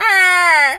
bird_vulture_croak_02.wav